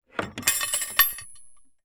Metal_62.wav